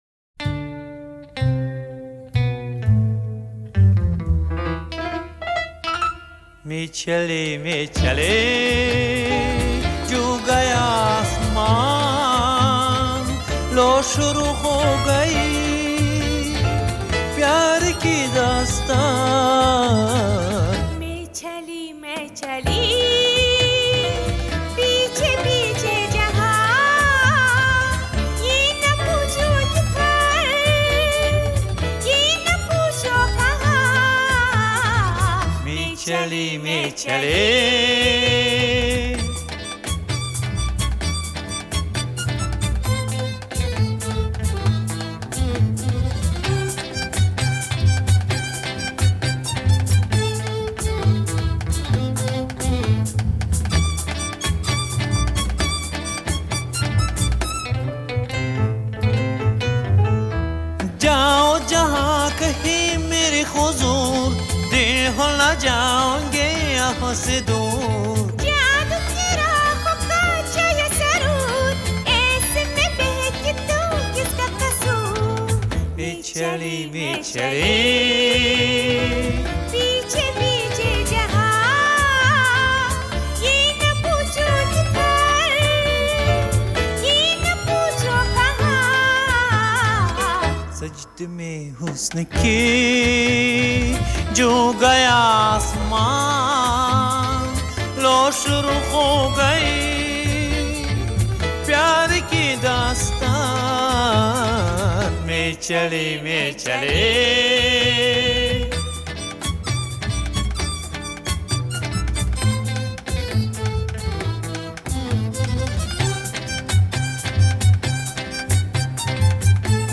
(Индийская нар. песня)